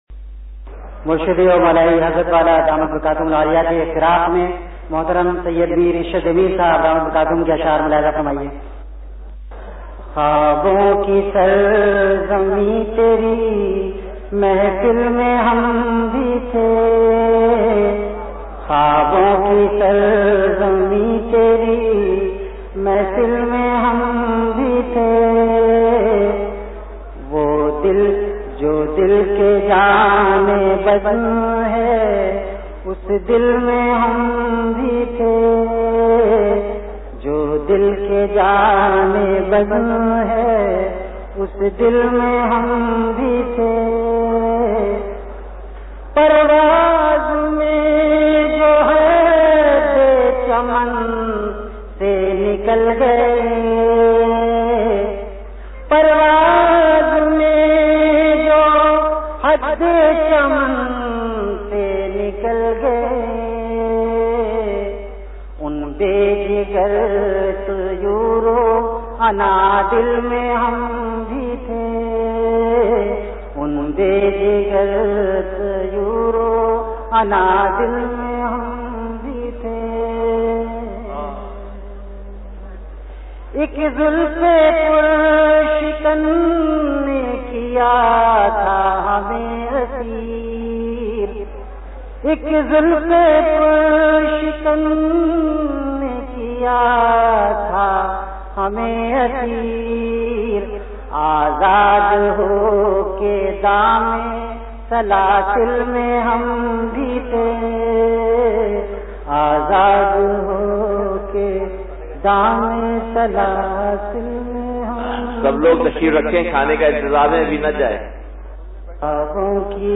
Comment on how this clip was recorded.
VenueKhanqah Imdadia Ashrafia Event / TimeAfter Isha Prayer